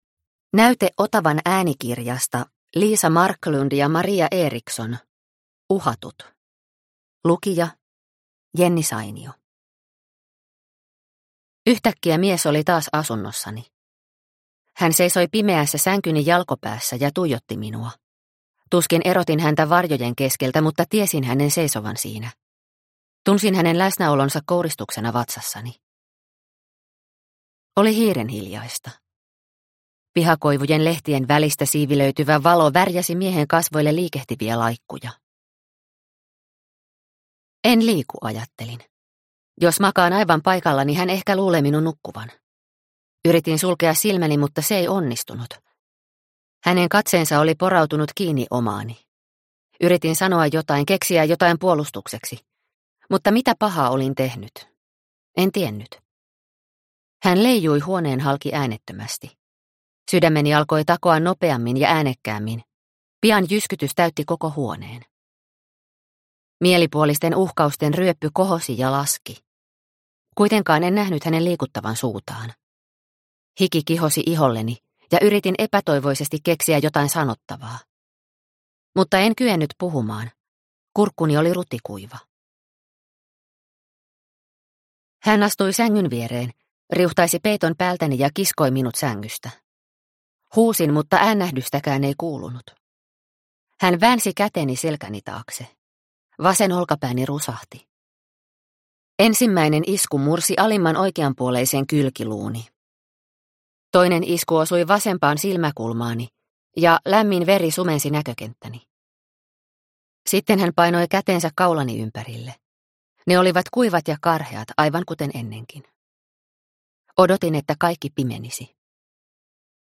Uhatut – Ljudbok – Laddas ner